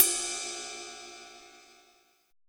POP RIDEEDG.wav